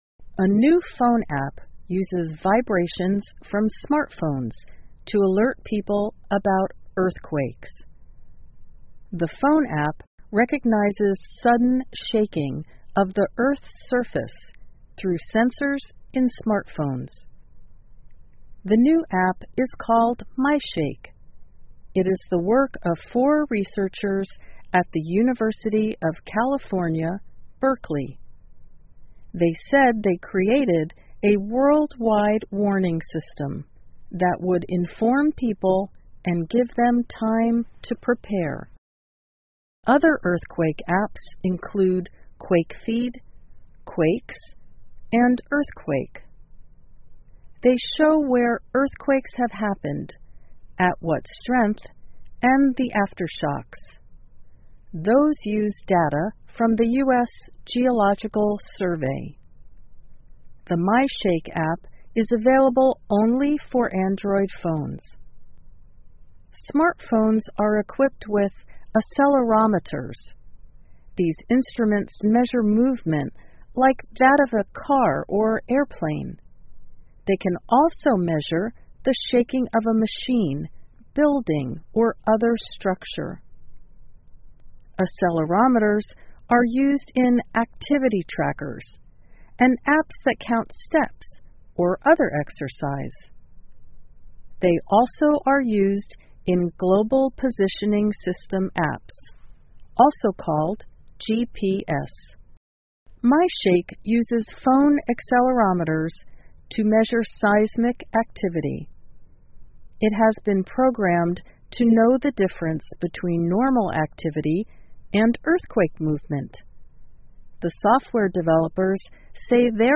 VOA慢速英语2016 能发出地震警报的手机应用 听力文件下载—在线英语听力室